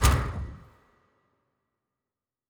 Fantasy Interface Sounds
Special Click 11.wav